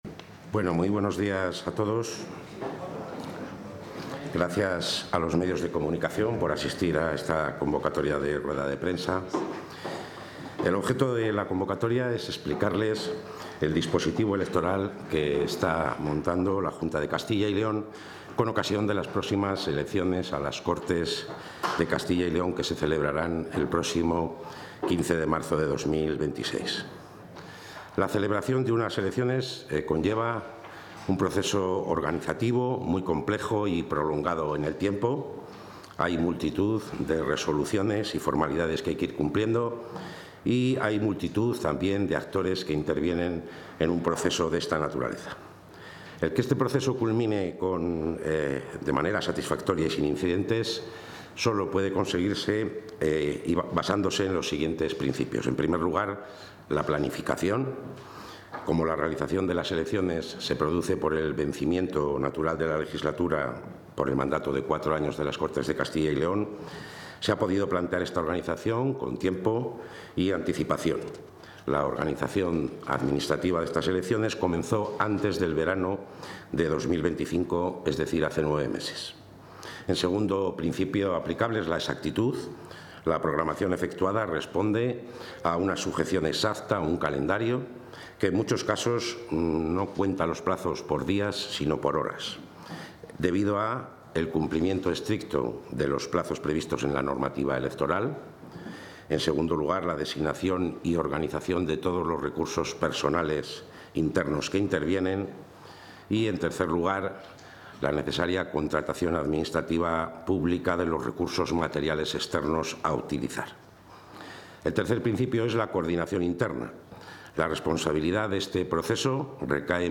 Galería Multimedia Presentación del dispositivo electoral Presentación del dispositivo electoral Presentación del dispositivo electoral Presentación del dispositivo electoral Intervención del consejero